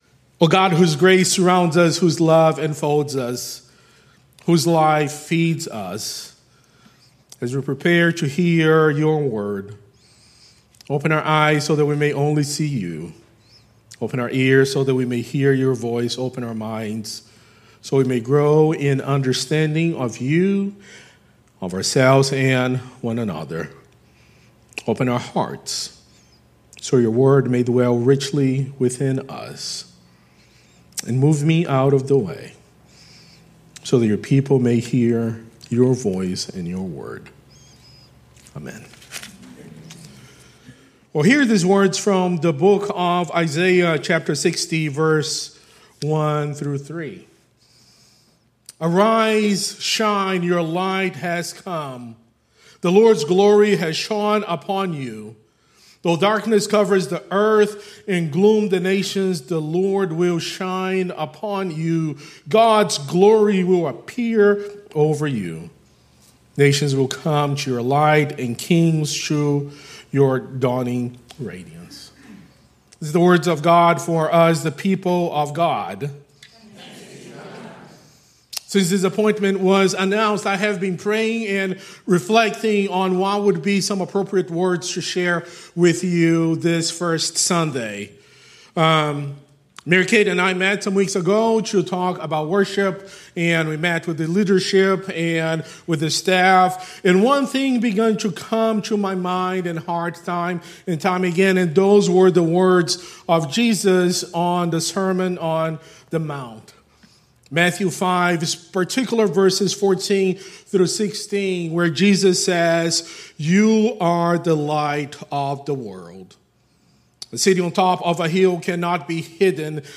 Sermons | Hilldale United Methodist Church